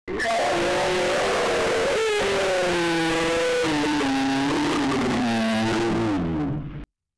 2D_Entities_Scream.wav